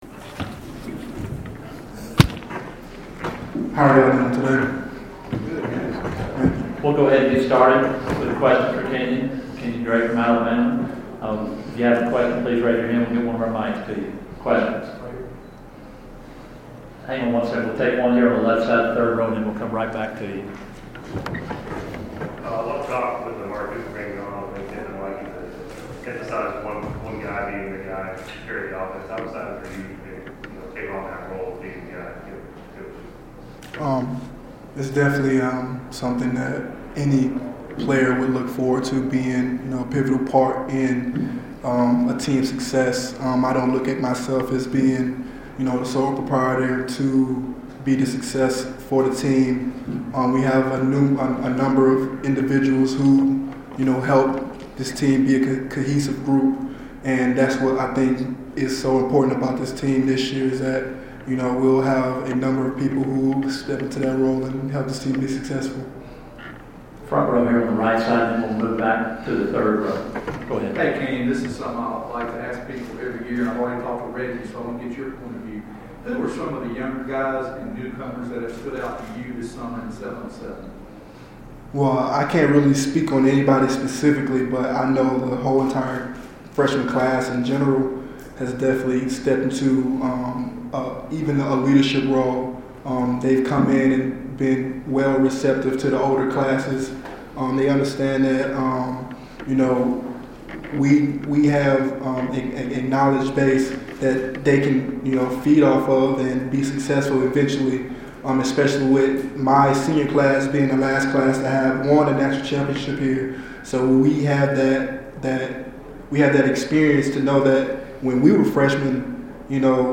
Alabama running back at SEC Media Days